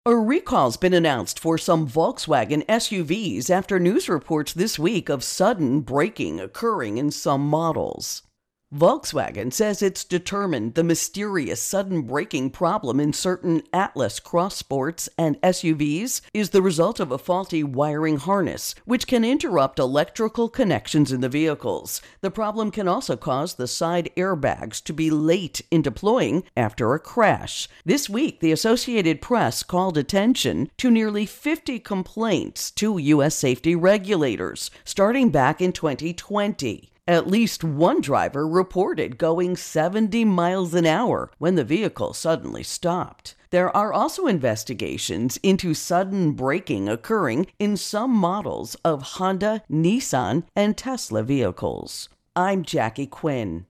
Volkswagen Mystery Braking Intro and Voicer